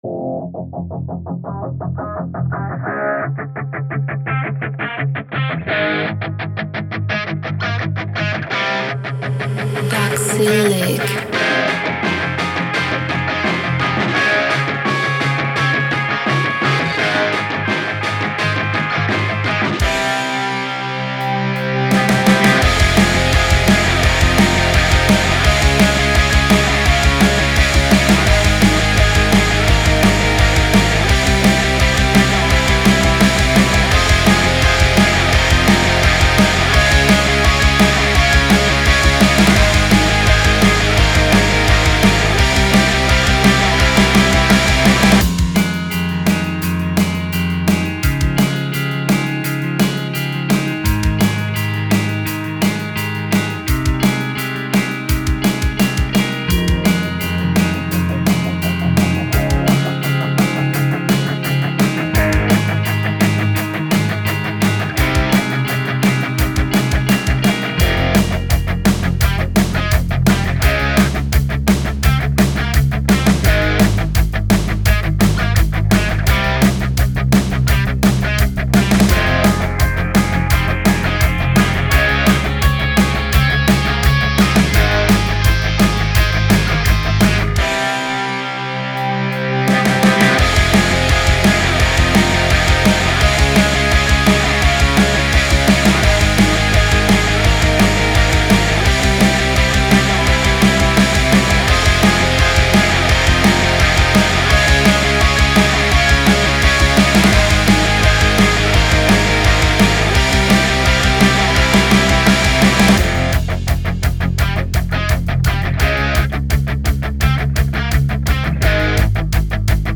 Hook ist richtig geil